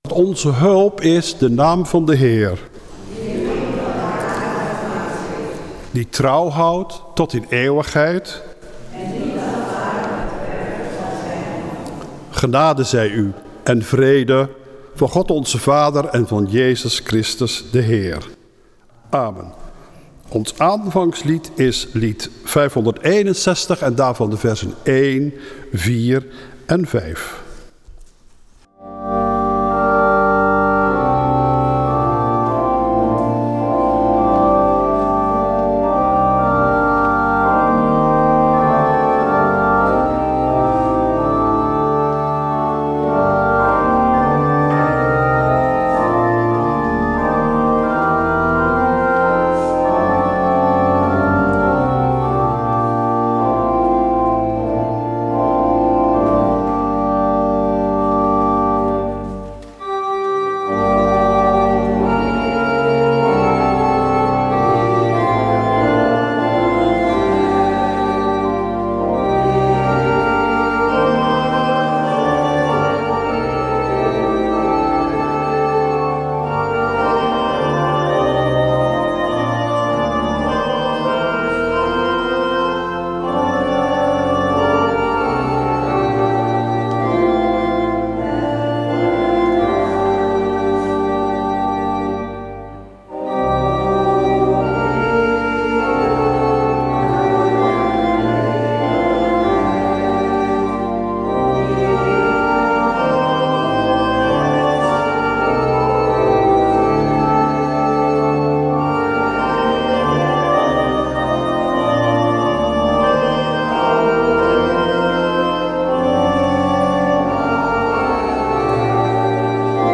Door omstandigheden is de samenzang voor de dienst helaas niet opgenomen.